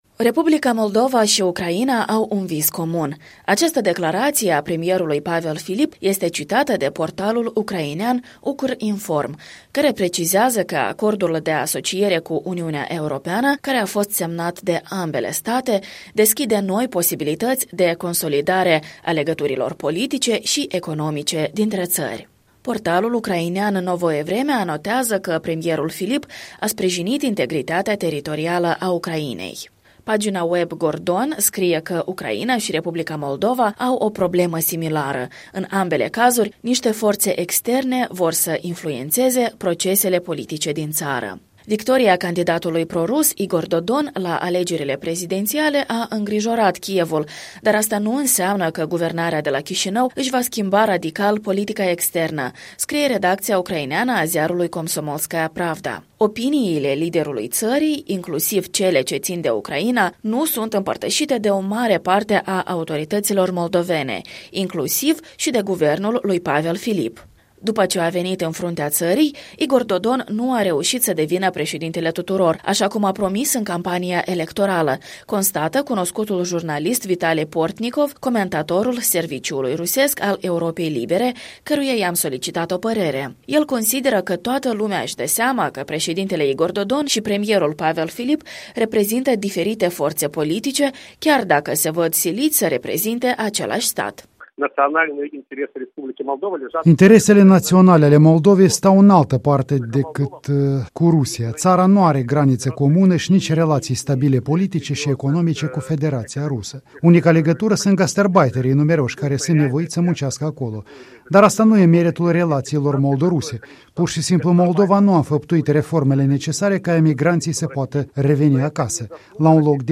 Presa ucraineană despre vizita premierului Pavel Filip la Kiev